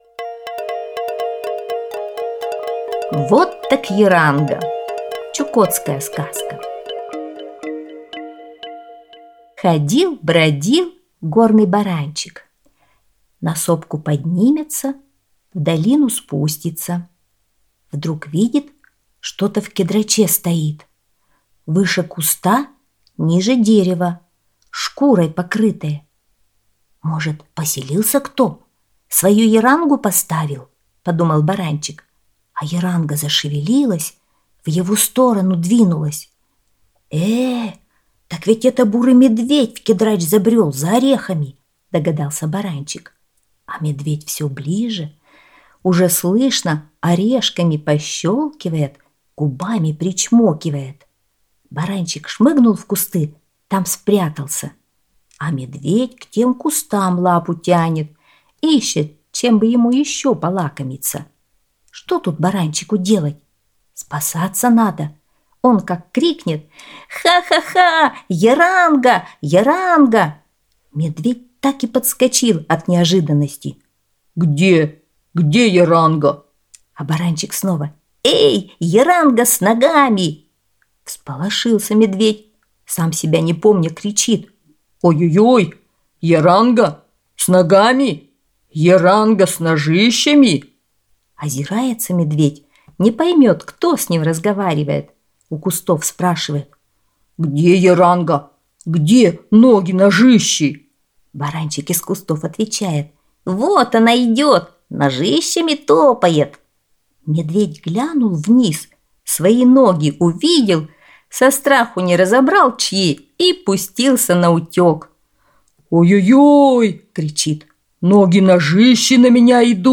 Чукотская аудиосказка